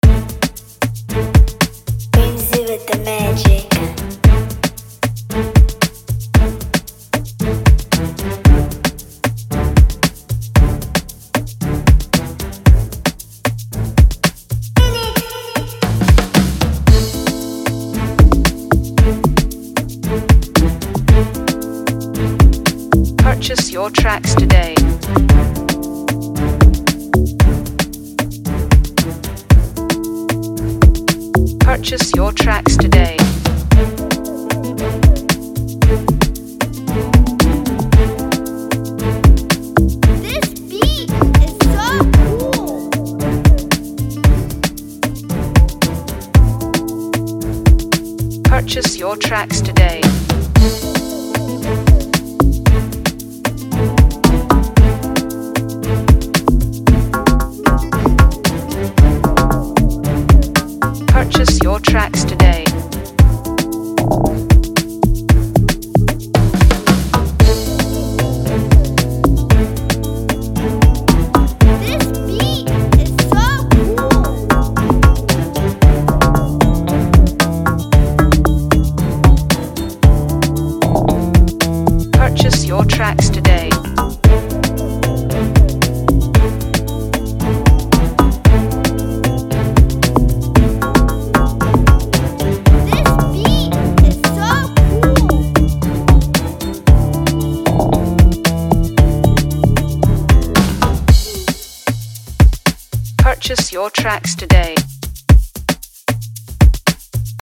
With infectious rhythms, pulsating drums, and melodic hooks